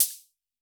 Index of /musicradar/retro-drum-machine-samples/Drums Hits/WEM Copicat
RDM_Copicat_MT40-OpHat01.wav